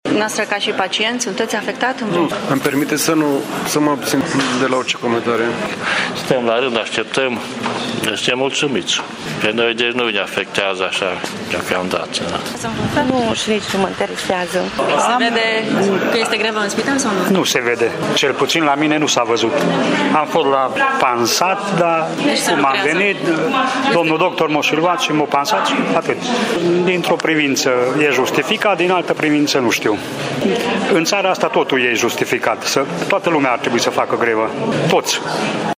Pacienții mureșeni nu au simțit că astăzi a fost grevă în Sănătate, dar s-au ferit să facă alte comentarii: